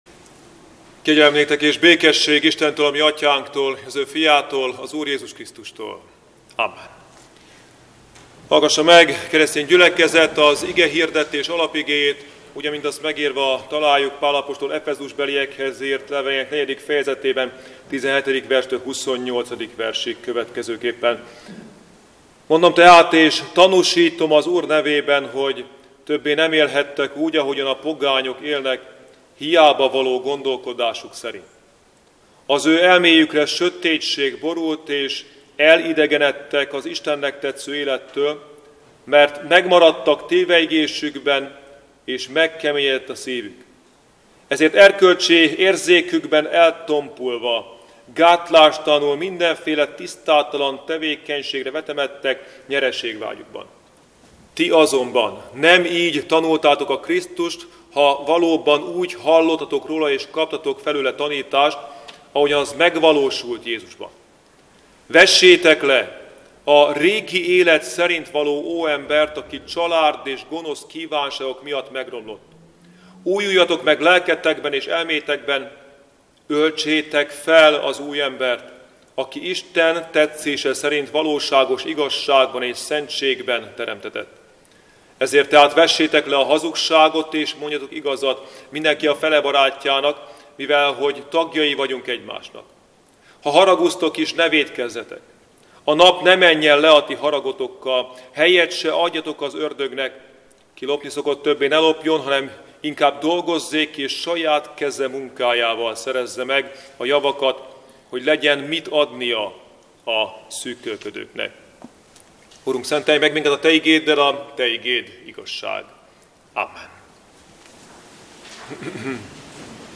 Szentháromság ünnepe utáni 19. vasárnap - Mindenki várakozva néz rád, és te idejében adsz nekik eledelt. (Zsolt 145,15)